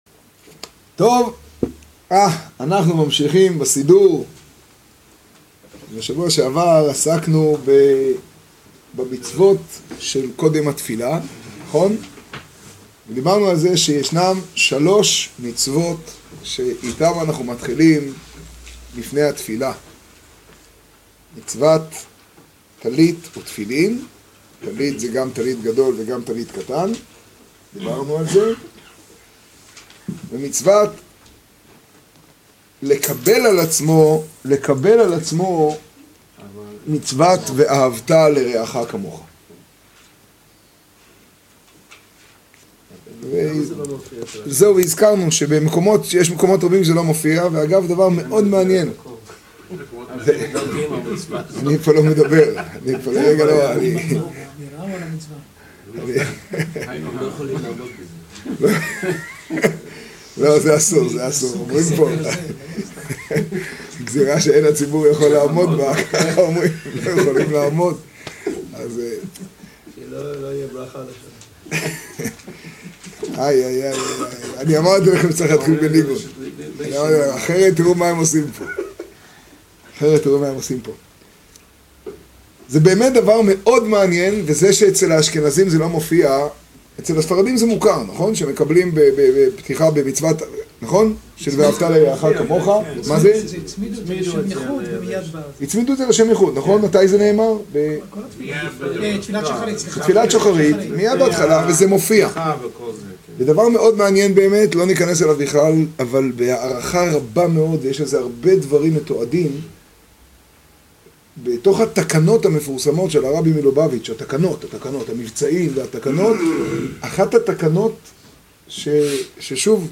השיעור בחצור, תשעד.